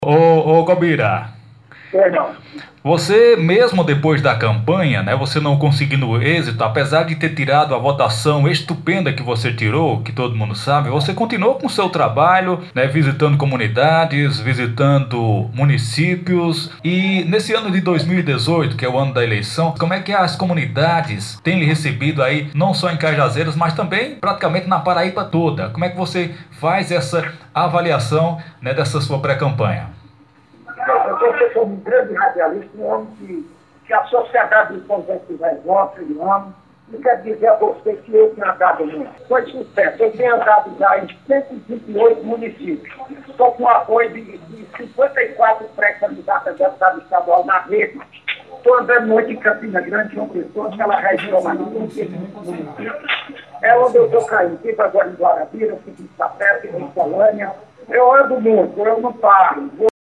Na entrevista ele  falou das dificuldades que enfrenta para conquistar o almejado cargo político com intuito de desenvolver seu trabalho e poder ajudar a classe mais pobre desde o Sertão até a capital da Paraíba.